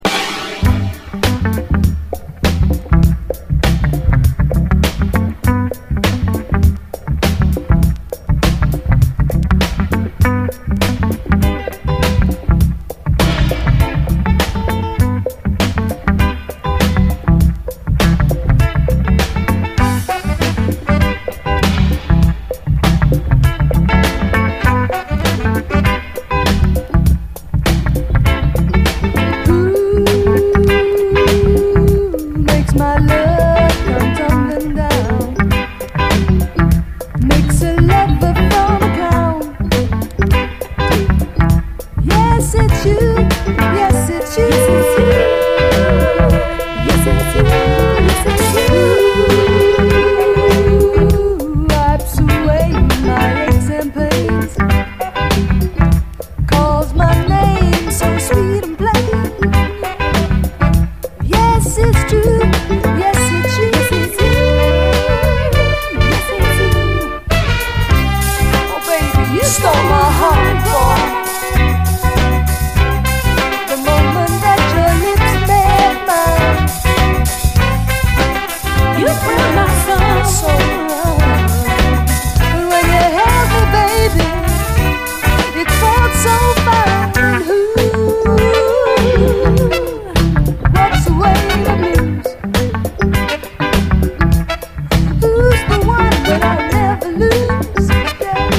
SOUL, 70's～ SOUL
人気カヴァー・ヴァージョン